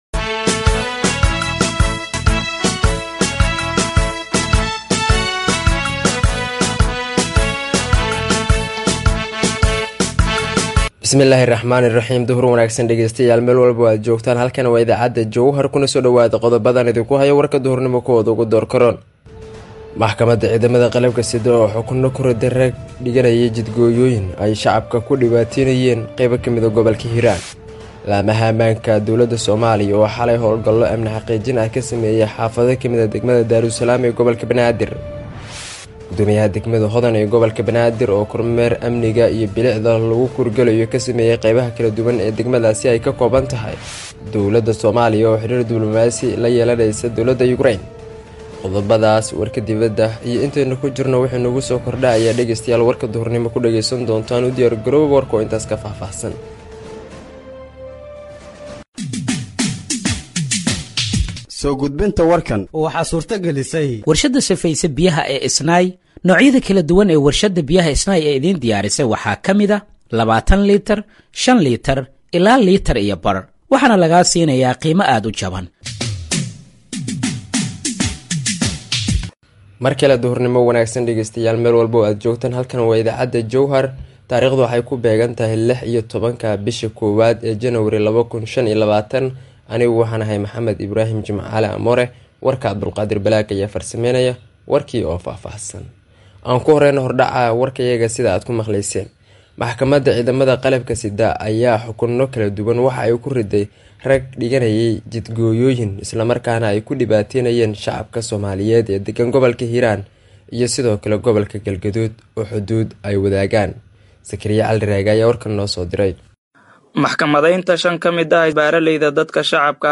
Dhageeyso Warka Duhurnimo ee Radiojowhar 16/01/2025
Halkaan Hoose ka Dhageeyso Warka Duhurnimo ee Radiojowhar